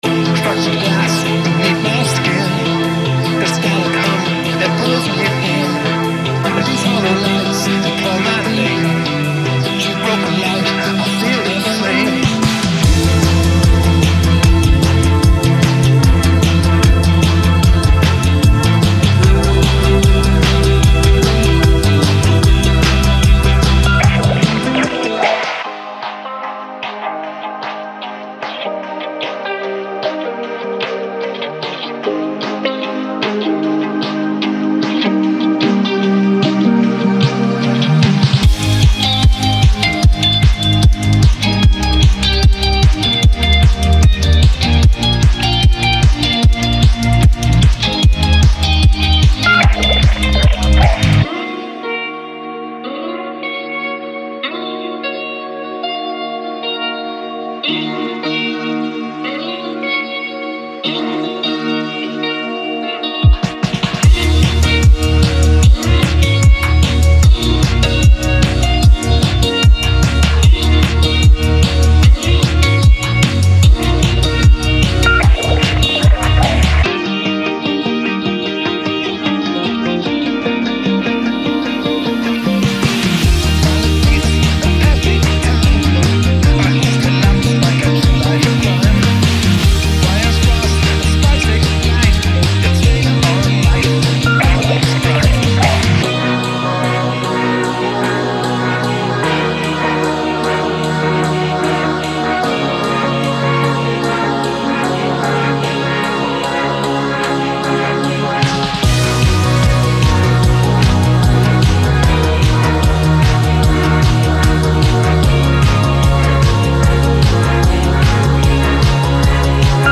Genre:Rock
ポストパンク、インディーロック、ダークウェイブに最適で、あなたの好きなジャンルに鋭さを加えるのにもぴったりです。
デモサウンドはコチラ↓